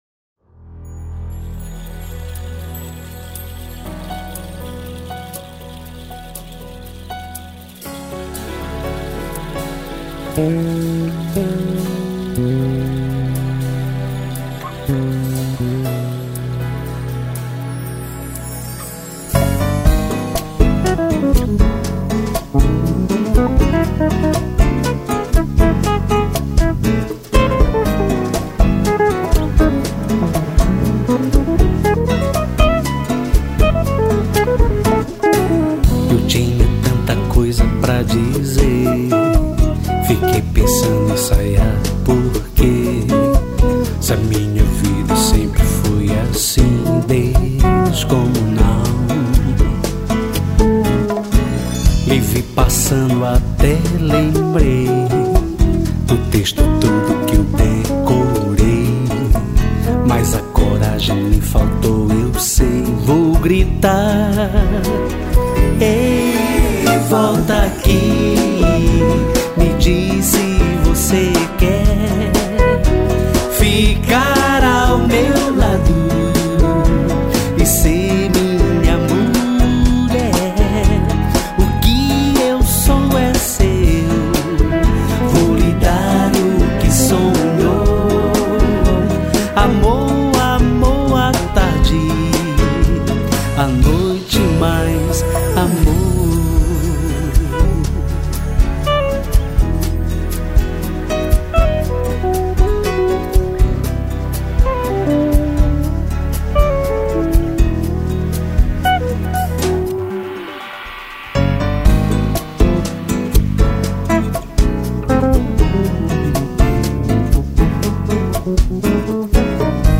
137   03:29:00   Faixa:     Bolero